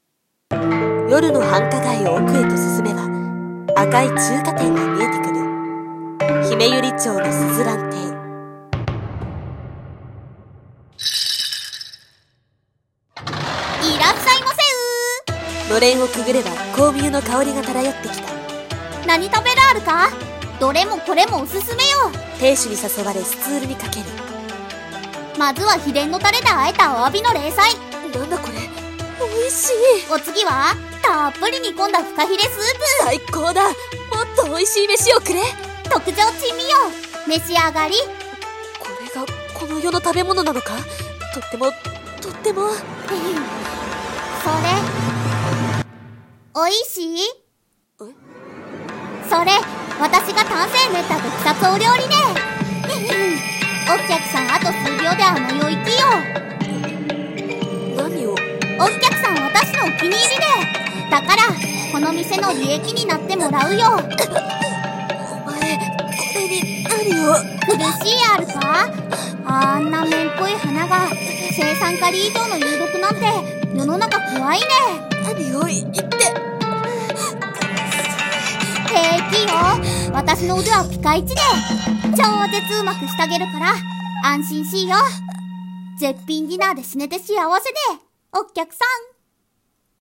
声劇】珍味の鈴蘭中華店